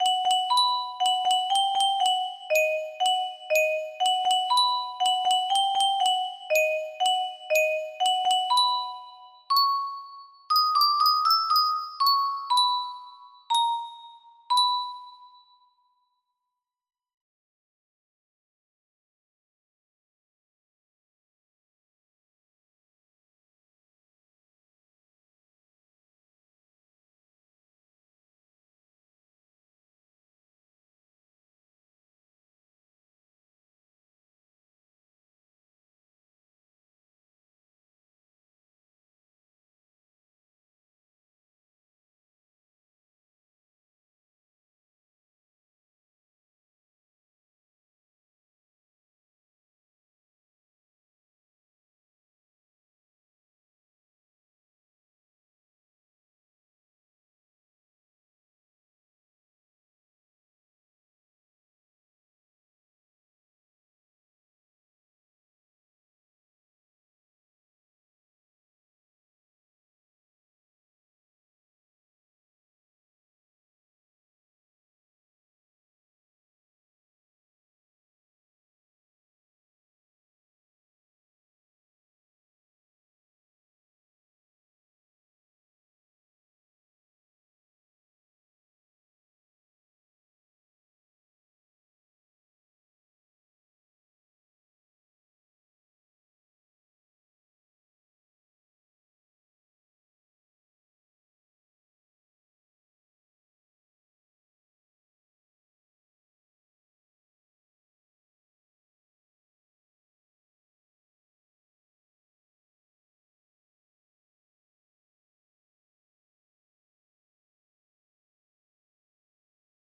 Full range 60
cute